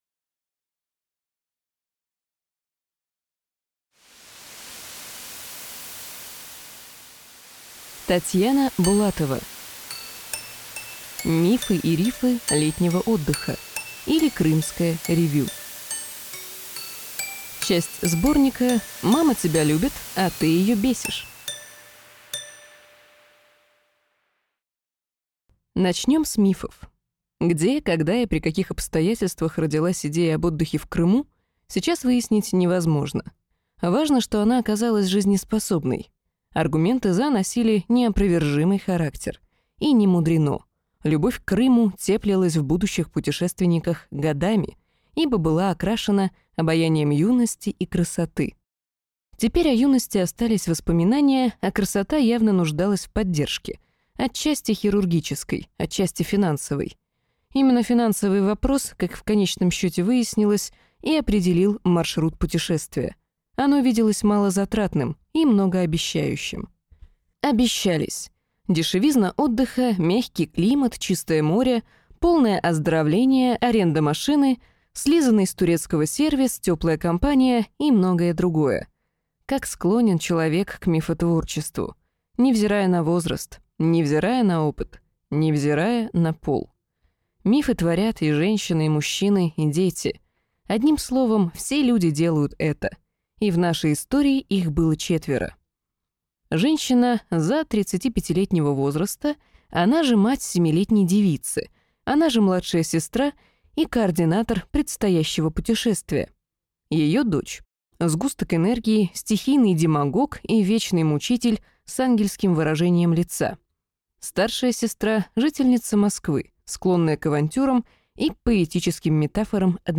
Аудиокнига Мифы и рифы летнего отдыха, или Крымское ревю | Библиотека аудиокниг